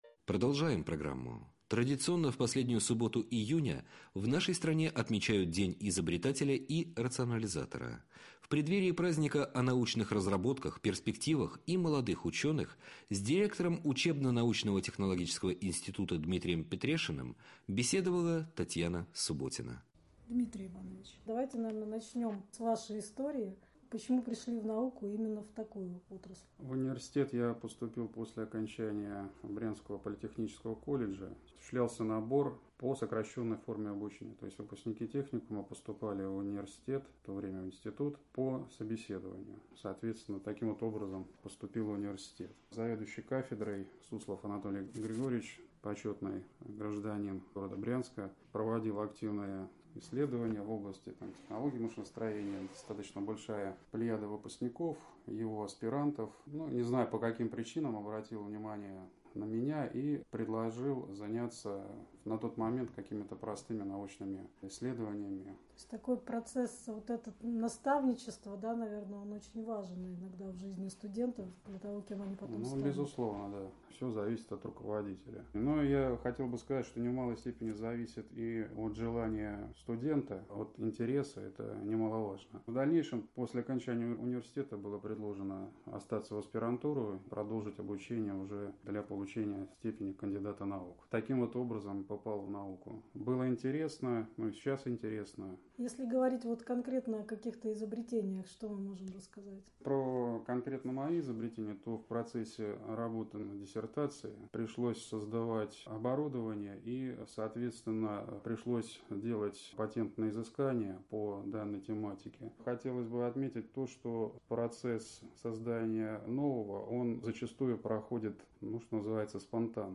• Интервью преподавателей и студентов нашего вуза для Радио России 28/09/2015